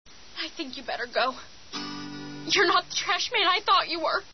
Taffy, addressing her would-be "enviro-conscious" suitor.